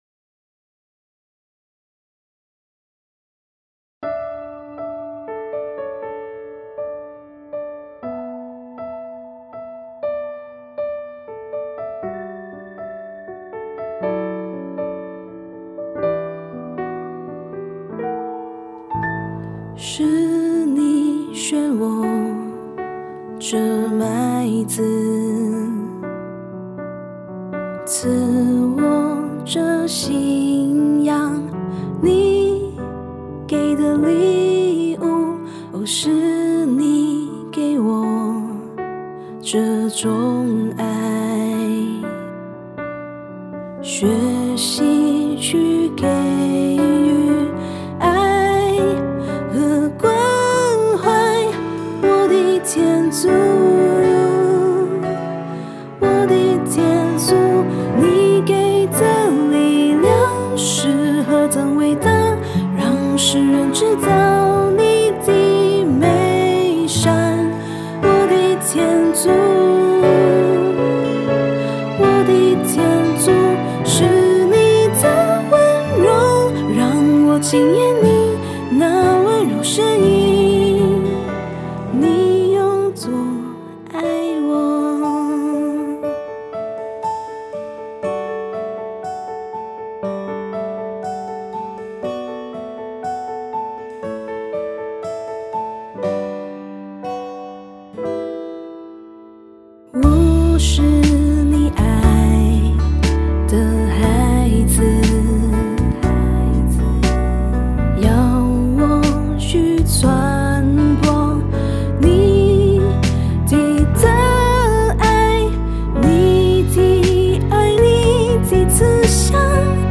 首歌主要是以比较现代流行歌的方式去作曲。